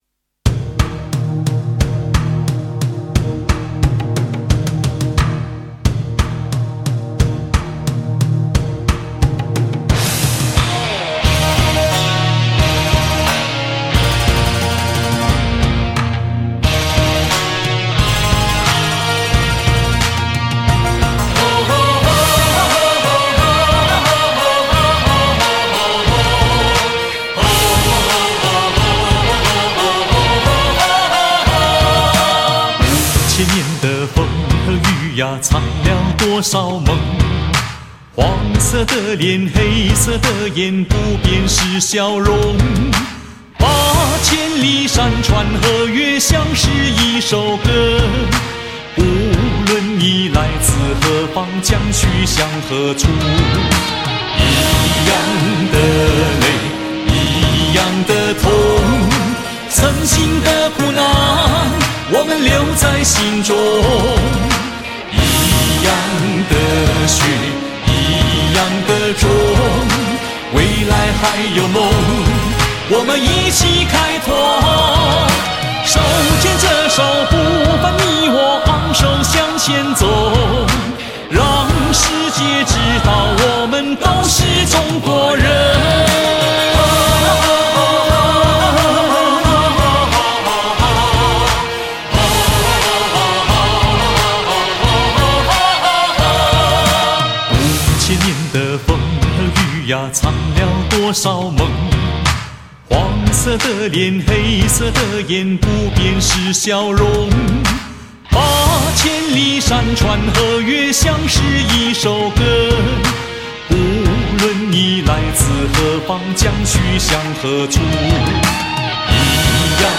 这张专辑是在新加坡灌录